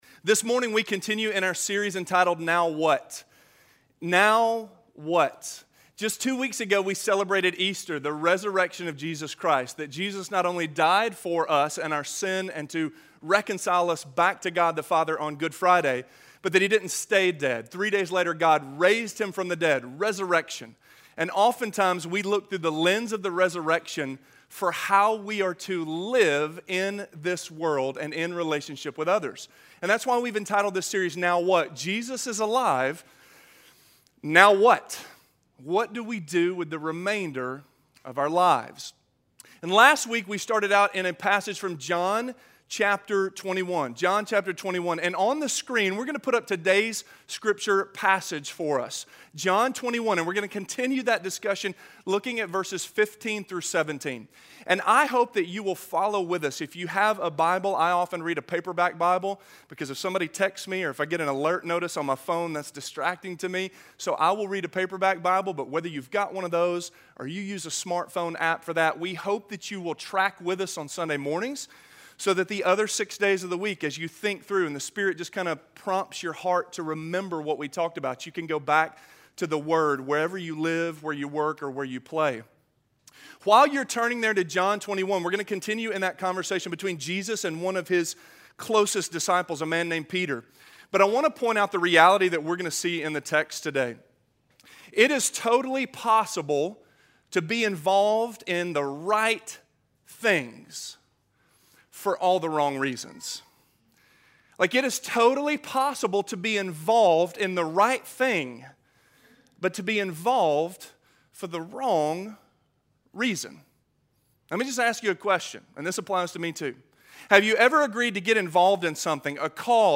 Do You Love Me More? - Sermon - Avenue South